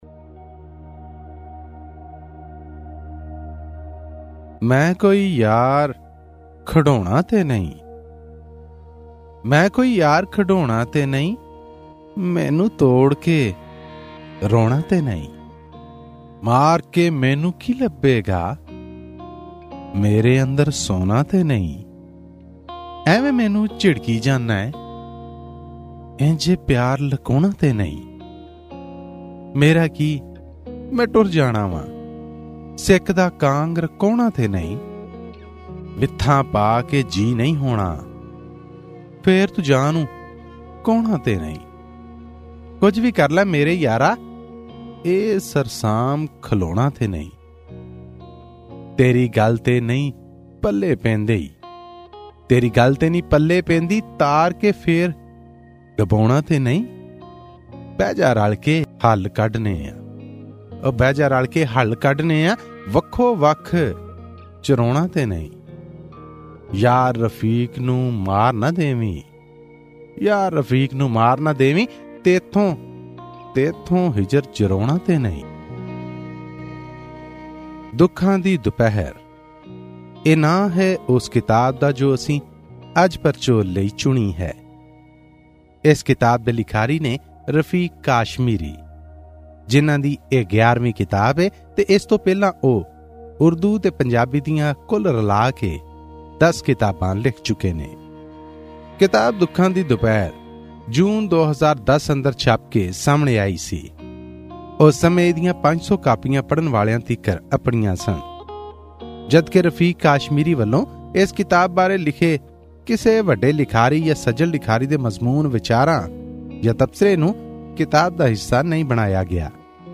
Pakistani Punjabi poetry book review Source: AAP